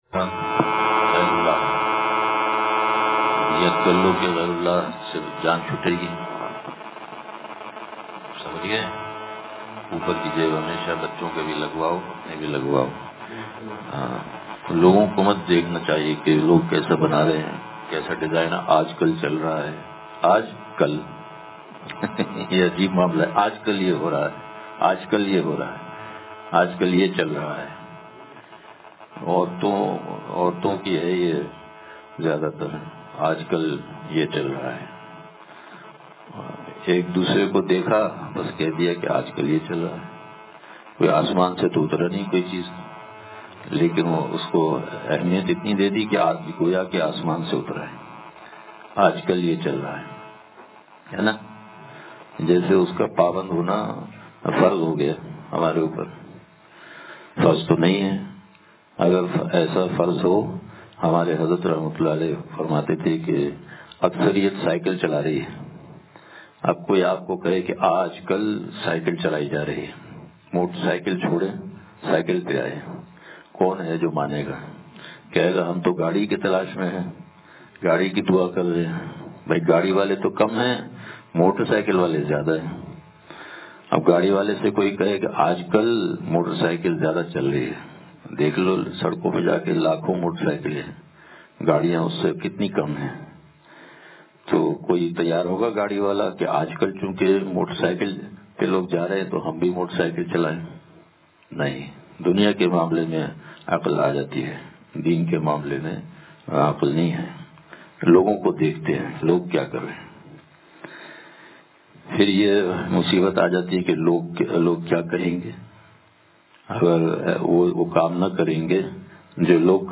بیان – ایمان کی مٹھاس